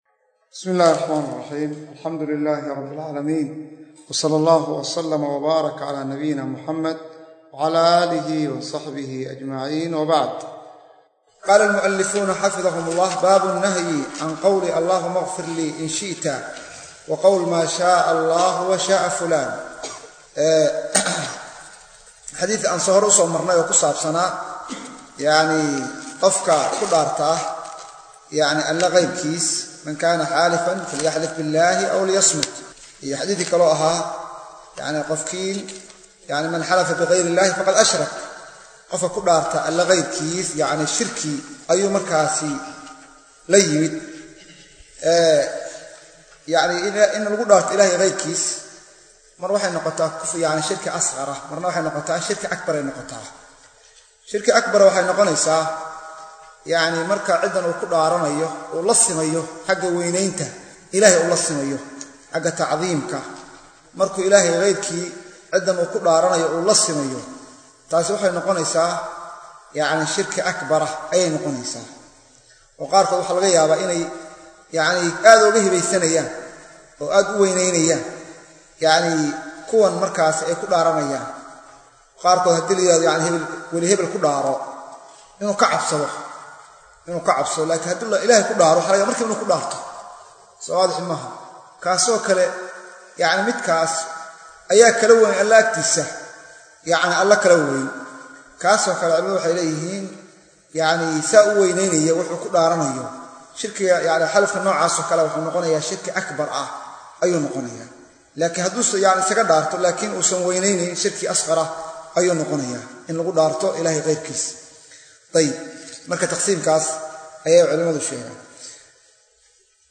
Sharaxa Kitaabka Al-Mukhtaar Min-Al-Muntakhab Min Axaadiith Al-Aadaab Wal Akhlaaq - Darsiga 16aad - Manhaj Online |